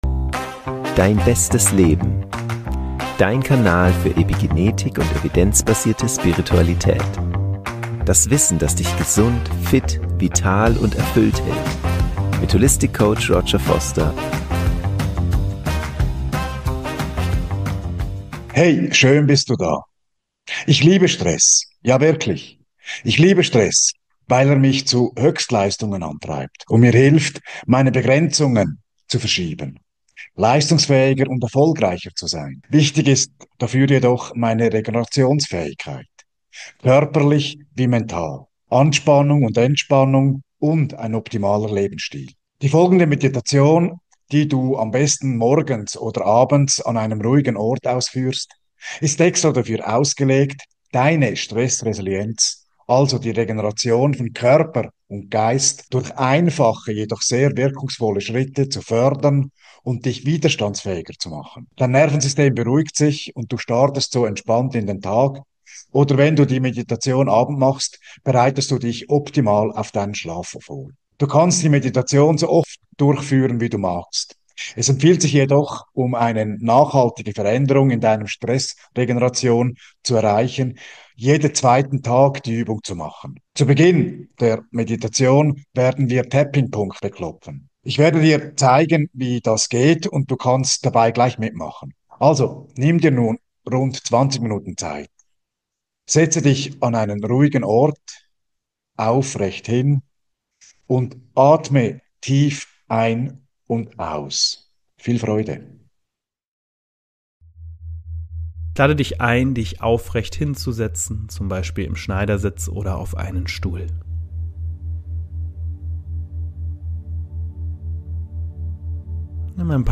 Die Meditation ist mit Binauralen Beats auf der Theta Frequenz (4 Hz) unterlegt.